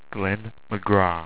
Pronounced
GLEN MAGRAA